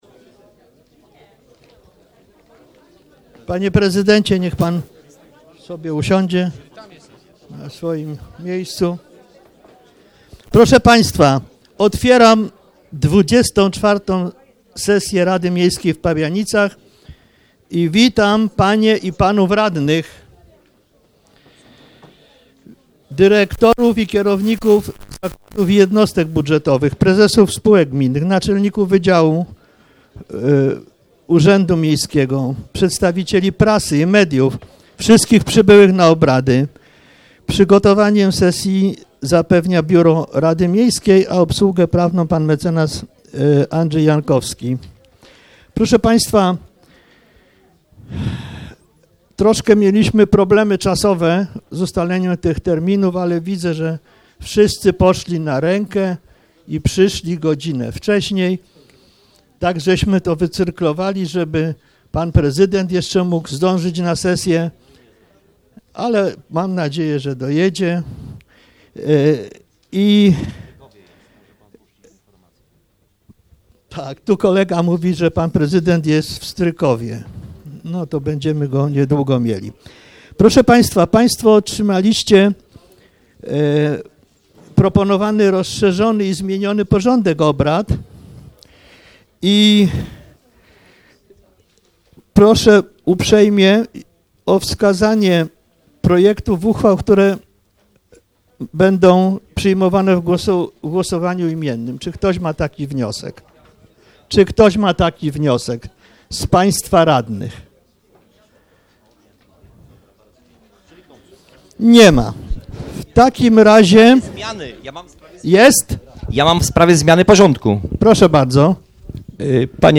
XXIV sesja Rady Miejskiej w Pabianicach - 3 marca 2016 r. - 2016 rok - Biuletyn Informacji Publicznej Urzędu Miejskiego w Pabianicach